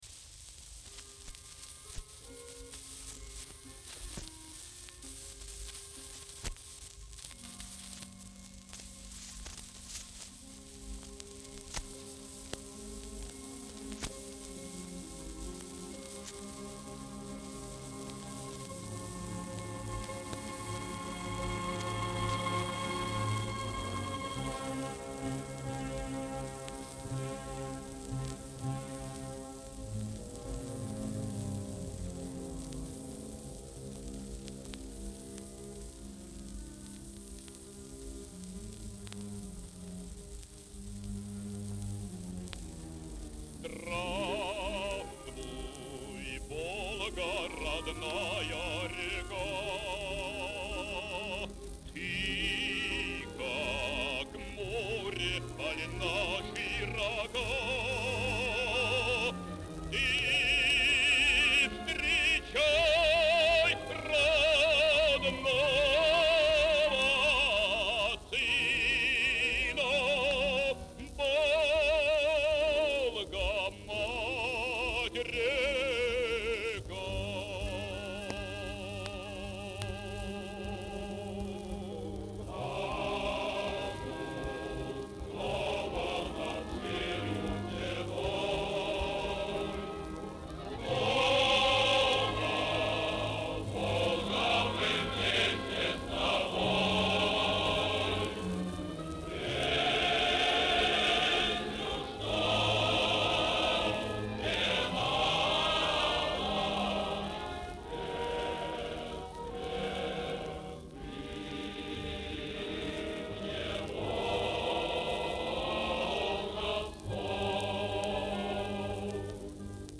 Описание: Эпическое произведение